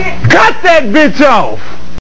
Burgundy Vox.wav